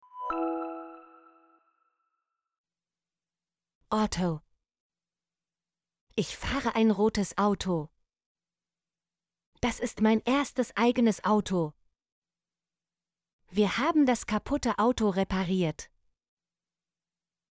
auto - Example Sentences & Pronunciation, German Frequency List